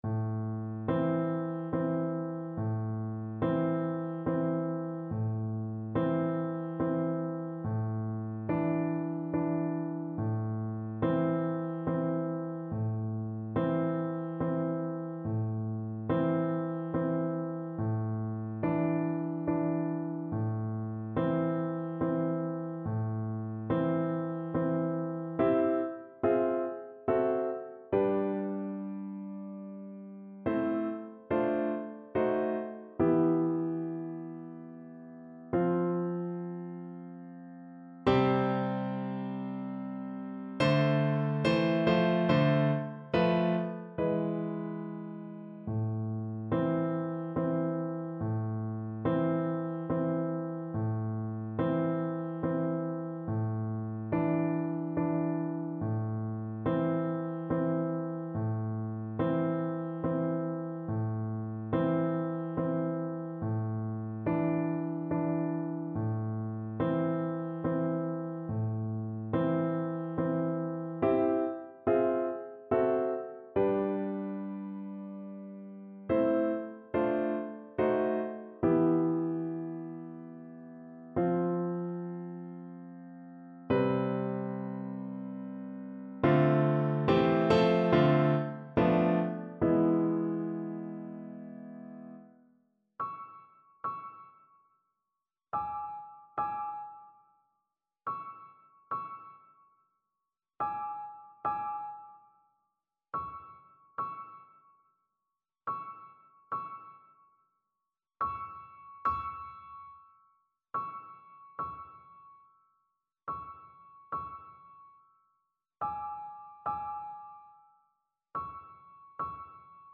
Flute version
3/4 (View more 3/4 Music)
Allegro Moderato (View more music marked Allegro)
Classical (View more Classical Flute Music)